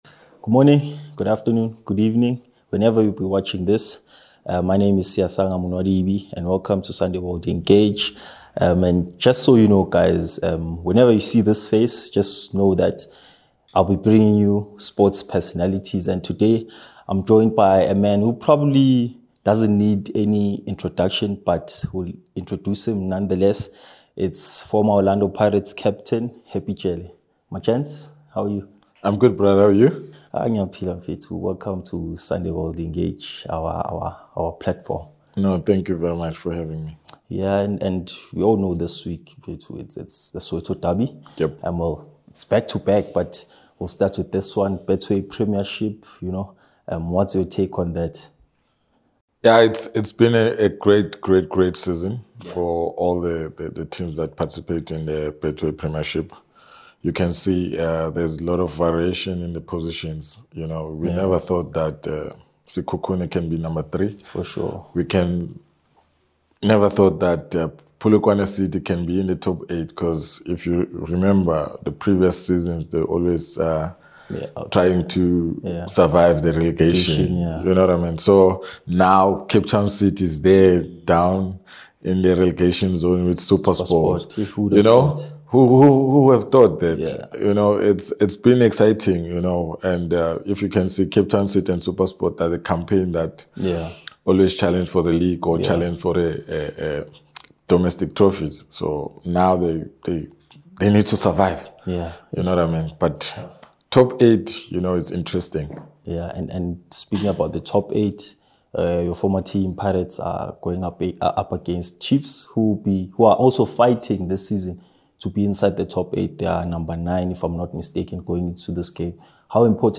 In this exclusive sit-down, the former captain reflects on the intensity of preparing for one of South Africa's most anticipated matches. From the behind-the-scenes challenges players face, to emotional highs and crushing lows, Jele reveals what it really takes to wear the jersey under pressure. Whether you're a die-hard Buccaneers supporter or a football fan craving insider perspective, this interview is raw, honest, and unforgettable.